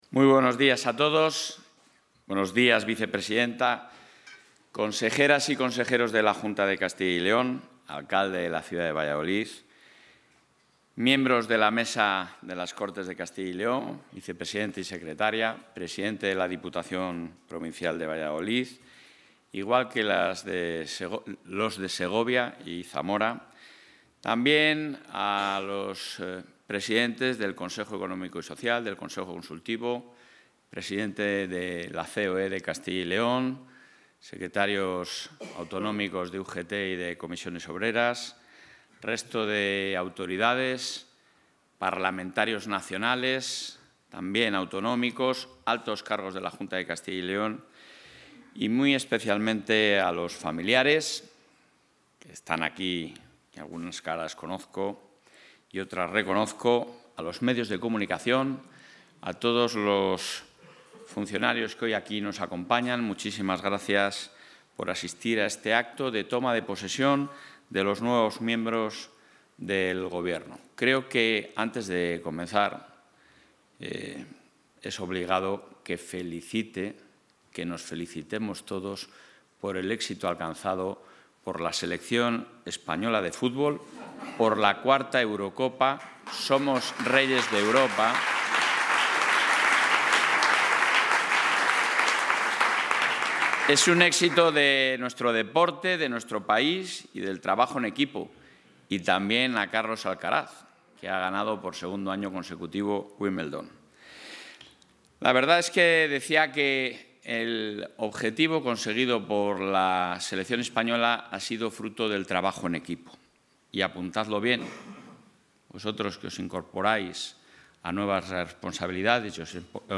Intervención del presidente de la Junta.
El presidente de la Junta de Castilla y León, Alfonso Fernández Mañueco, ha presidido hoy, en la sede de la Presidencia, el acto institucional de toma de posesión de los nuevos miembros del Ejecutivo autonómico, a los que ha pedido el máximo esfuerzo para continuar con el profundo proceso de transformación de la Comunidad y así consolidarla como tierra de oportunidades y de futuro.